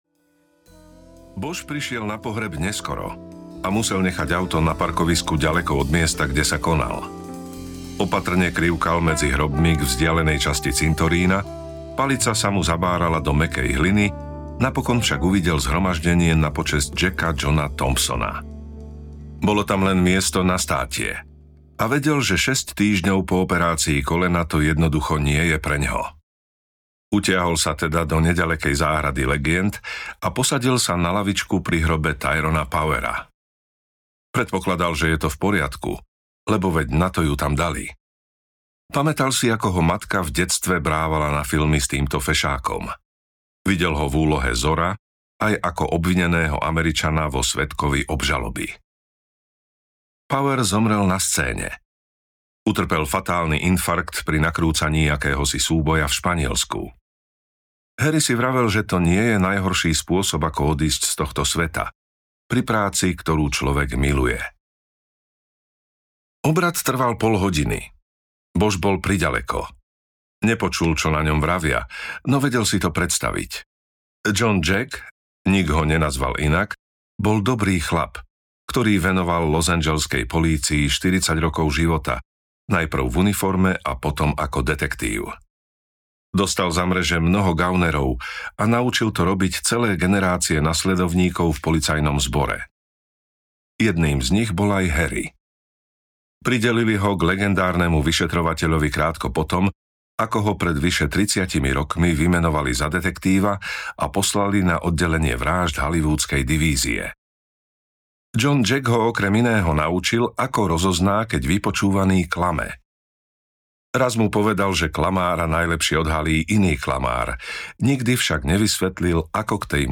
Temný plameň audiokniha
Ukázka z knihy